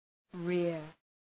Προφορά
{rıər}